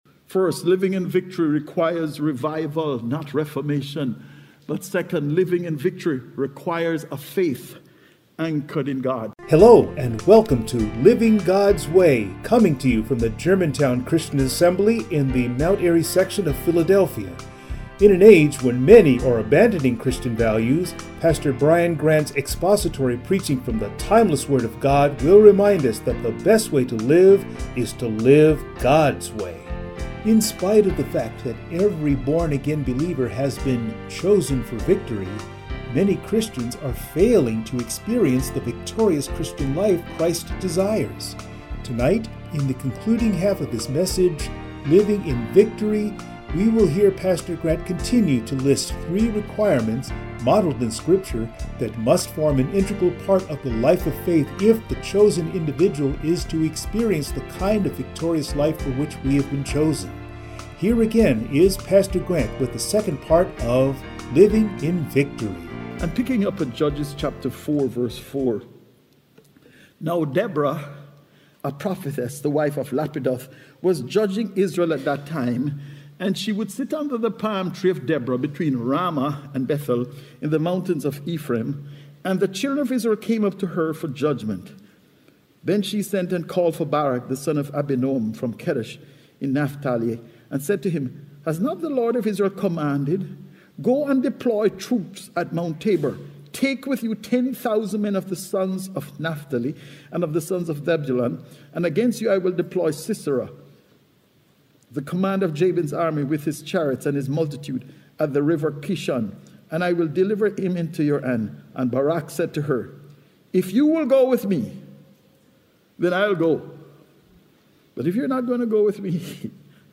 Passage: Judges 4 & 5 Service Type: Sunday Morning